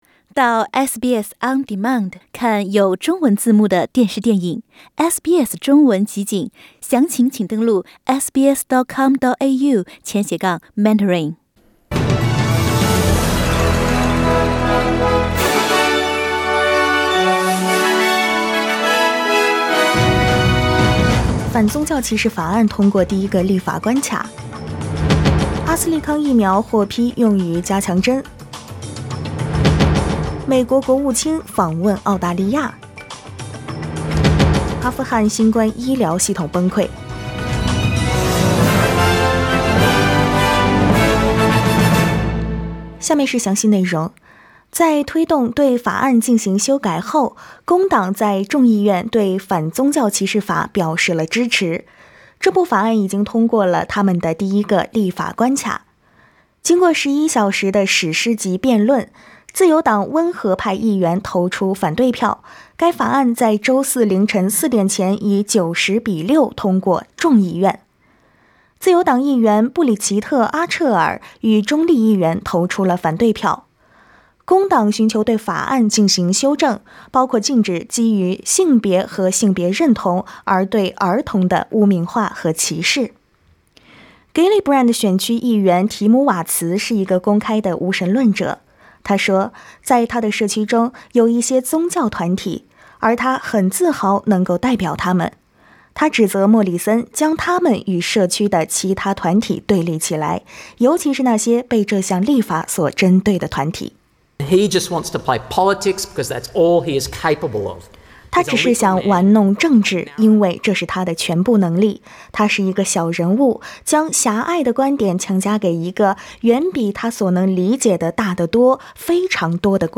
SBS早新闻（2月10日）
SBS Mandarin morning news Source: Getty Images